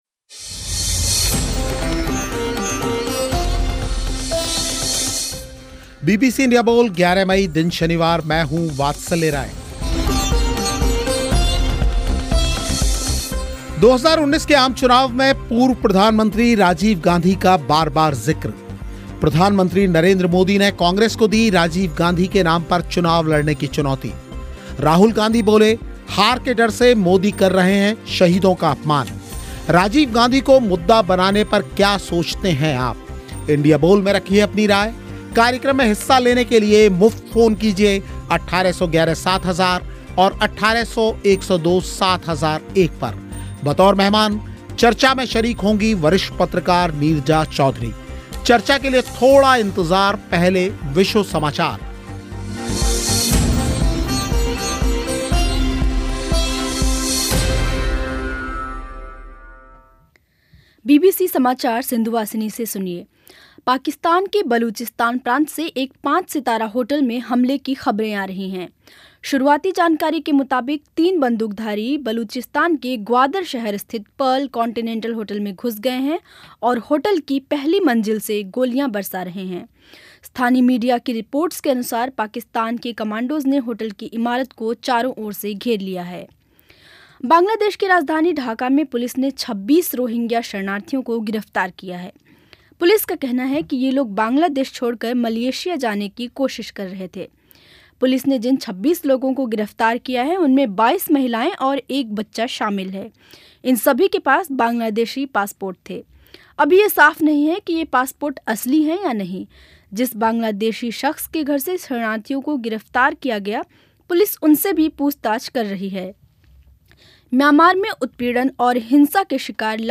इंडिया बोल में इसी विषय पर हुई चर्चा
श्रोताओं ने भी रखी अपनी बात